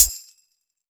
• Huge Hi Hat D# Key 81.wav
Royality free pedal hi-hat sound tuned to the D# note. Loudest frequency: 8372Hz
huge-hi-hat-d-sharp-key-81-XZm.wav